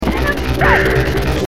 Some of the voices were also changed, here is an example with one of King's special moves.